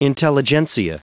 Prononciation du mot intelligentsia en anglais (fichier audio)